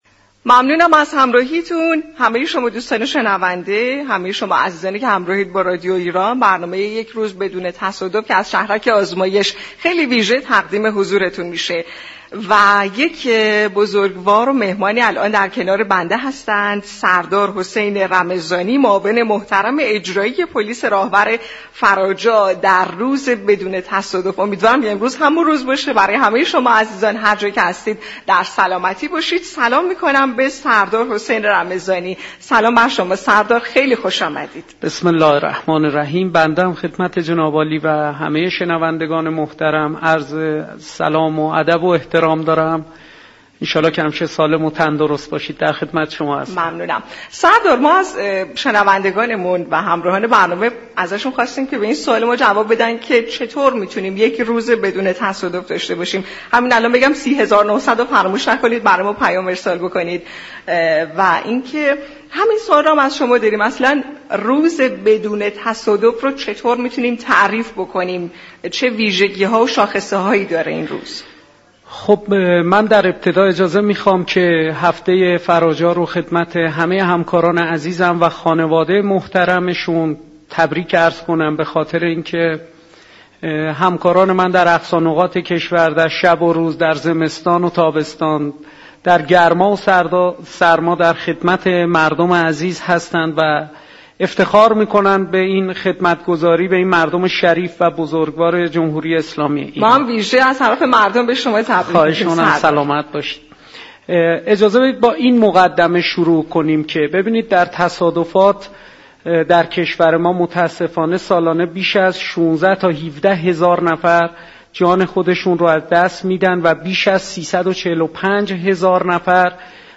معاون اجرایی پلیس راهور فراجا گفت: سرعت و سبقت غیرمجاز علل اصلی تصادفات است.